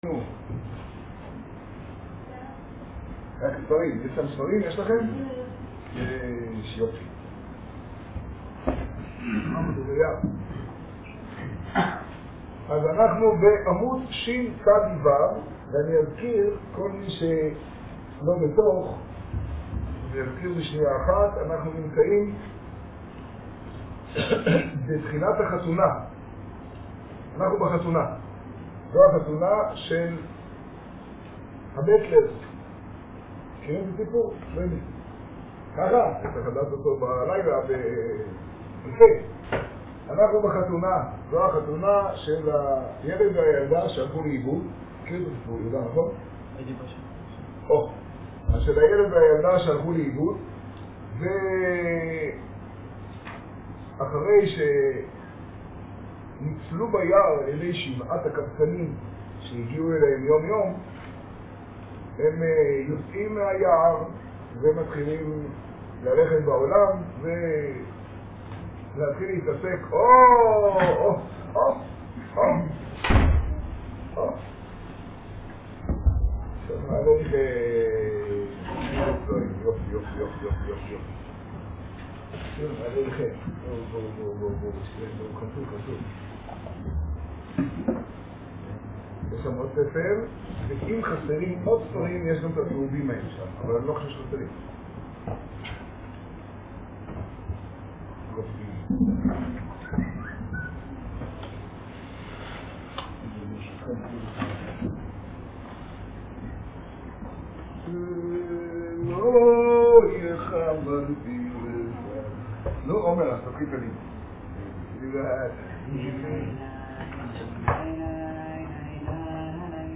מעשה משבעה בעטלרס, השיעור במגדל, פרשת צו תשעה.